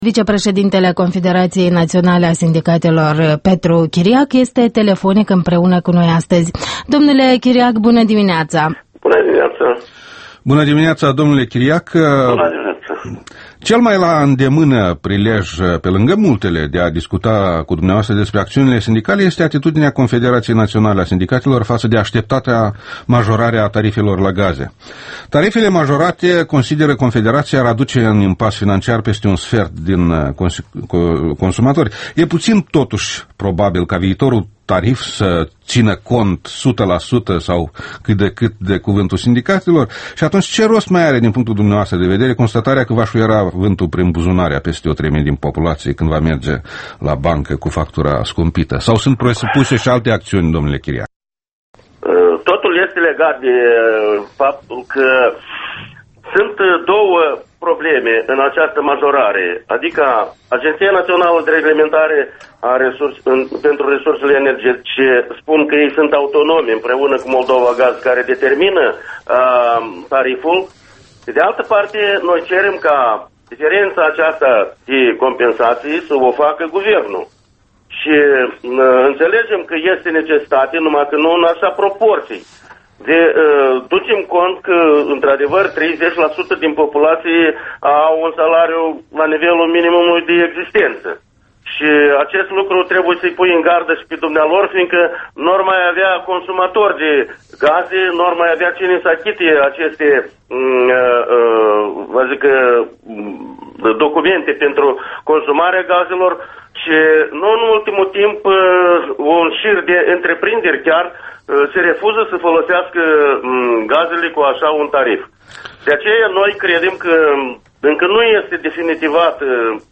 Interviul matinal EL